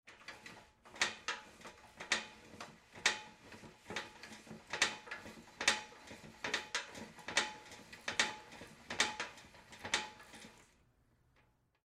Звуки отвертки
Звук вращения крестообразной отвертки при закручивании шурупа в металл